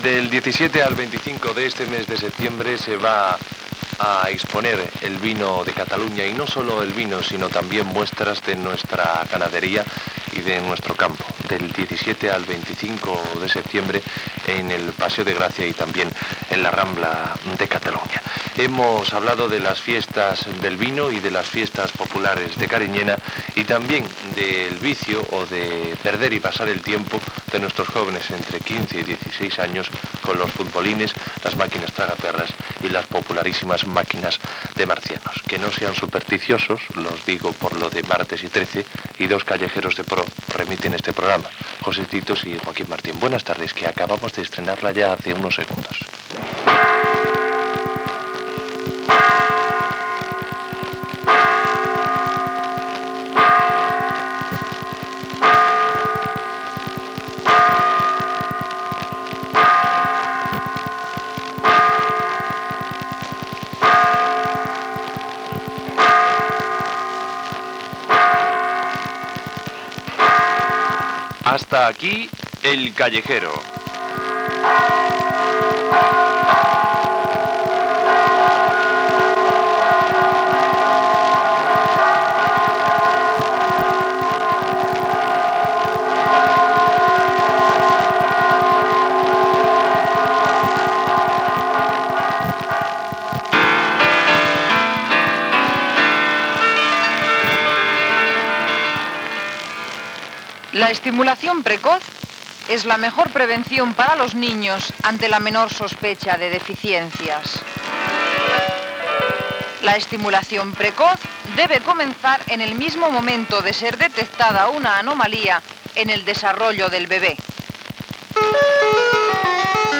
Final de "El callejero", publicitat, programes diaris de l'emissora i inici de "Besòs enllà"
Informatiu
FM